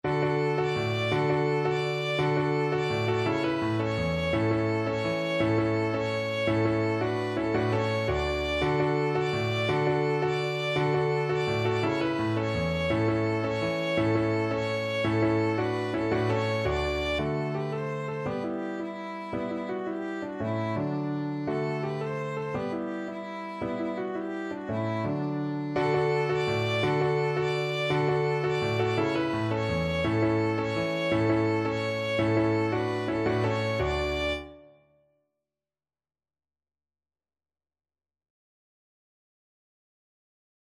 ViolinViolin
3/4 (View more 3/4 Music)
Fast and energetic =c.168
D5-D6
D major (Sounding Pitch) (View more D major Music for Violin )
Traditional (View more Traditional Violin Music)